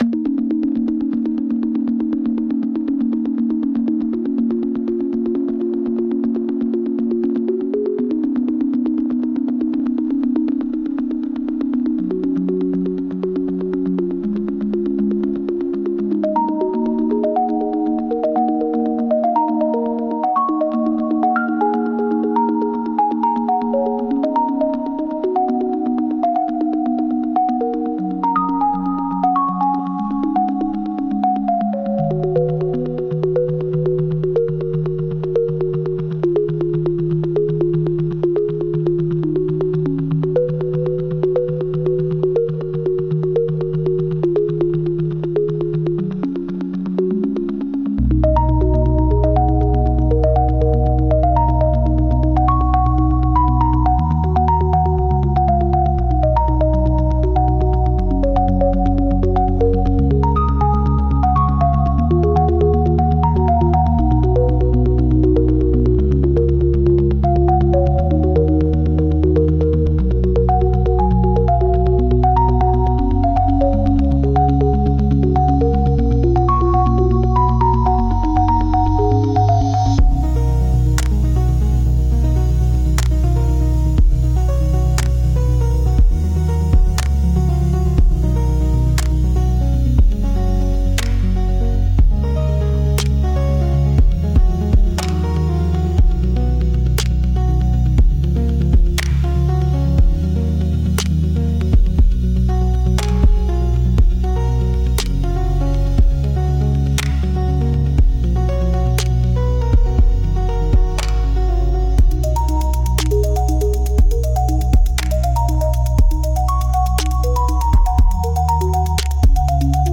雨音と蝉時雨